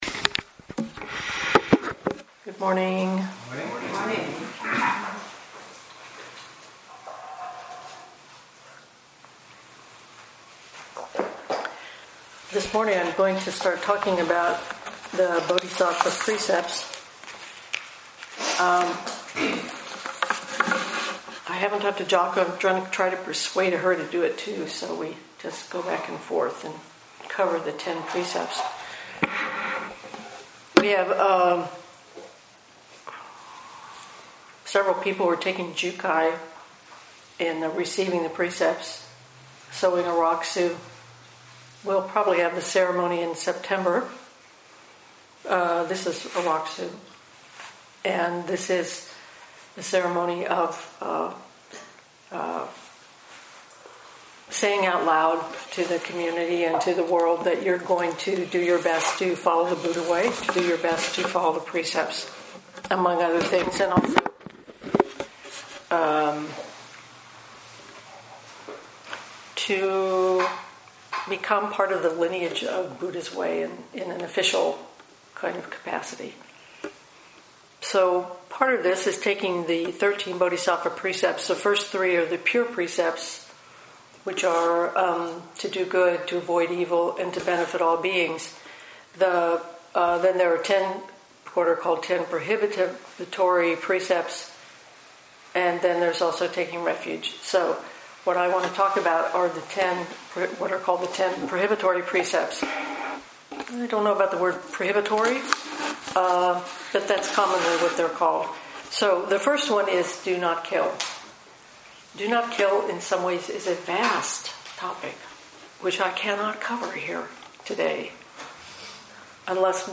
2017 in Dharma Talks